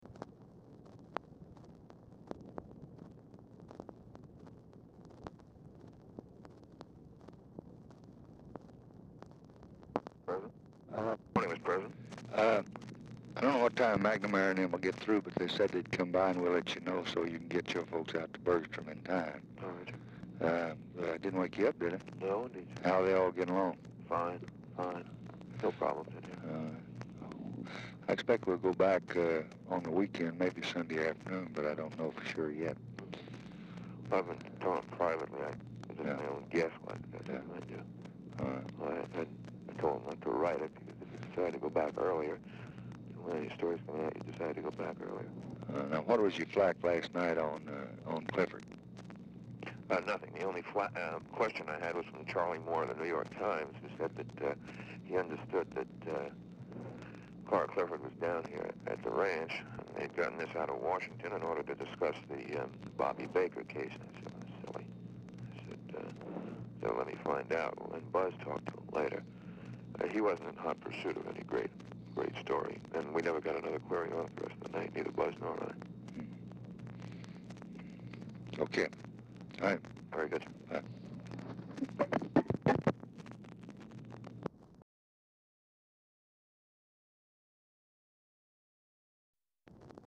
Telephone conversation
Format Dictation belt
LBJ Ranch, near Stonewall, Texas